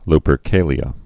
(lpər-kālē-ə, -kālyə)